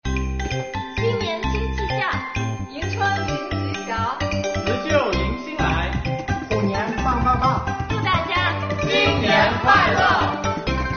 国家税务总局桂林市秀峰区税务局青年干部表示，新的一年，将继续守初心担使命，为纳税人缴费人办实事解难题，为推动新发展阶段税收现代化建设贡献更大税务力量。